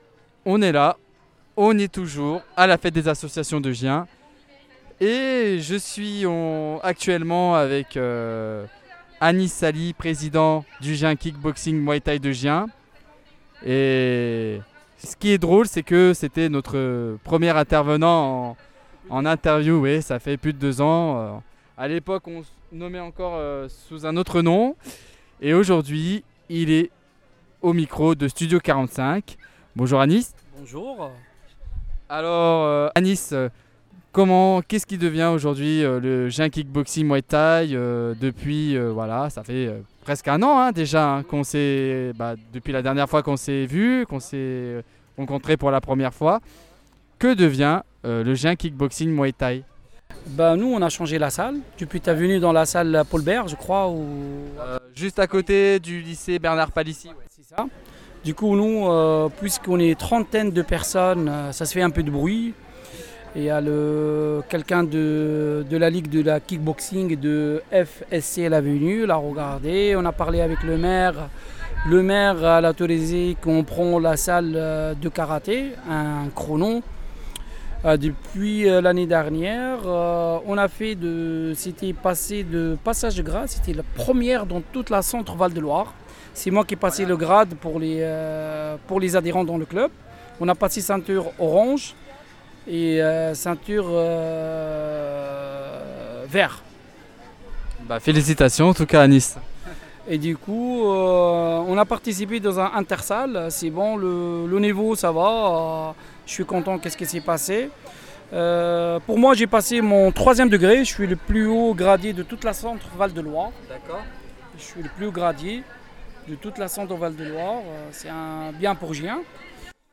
Fêtes des associations de Gien 2025 - Gien Kick-Boxing Muay-Thaï